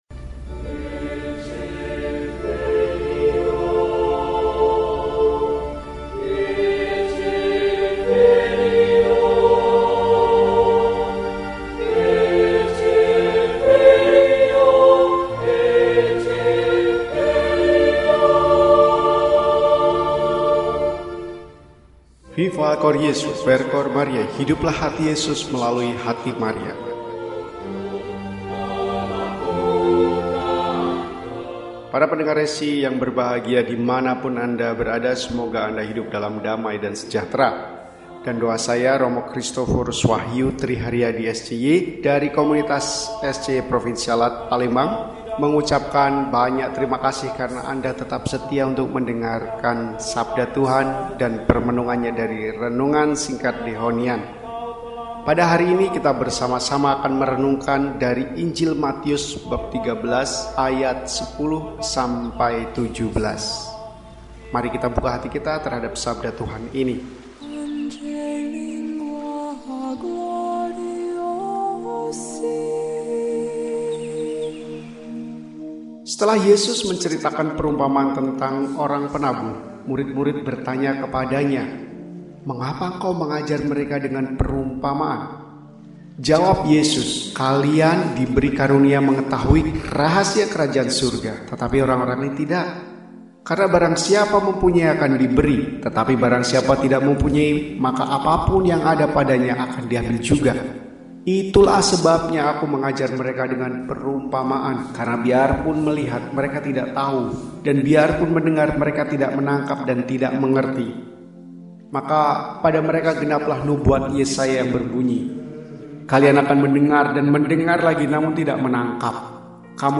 Kamis, 24 Juli 2025 – Hari Biasa Pekan XVI – RESI (Renungan Singkat) DEHONIAN